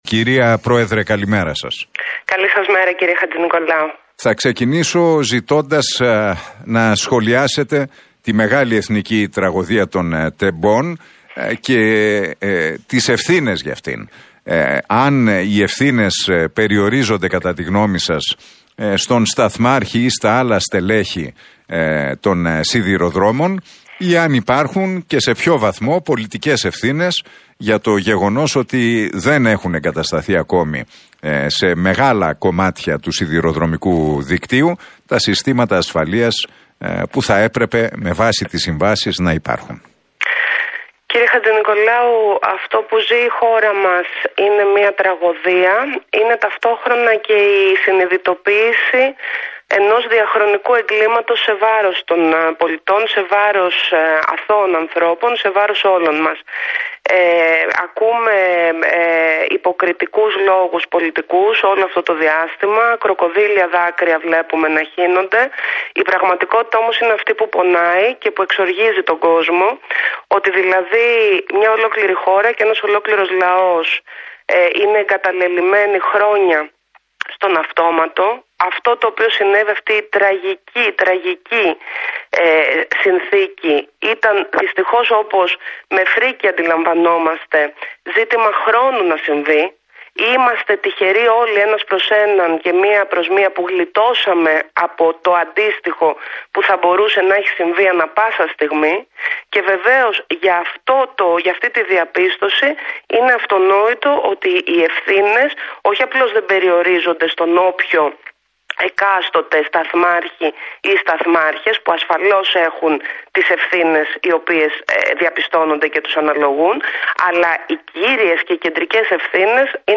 Η επικεφαλής της Πλεύσης Ελευθερίας, Ζωή Κωνσταντοπούλου σε συνέντευξη που παραχώρησε στον ραδιοφωνικό σταθμό Realfm 97,8 και στον Νίκο Χατζηνικολάου δήλωσε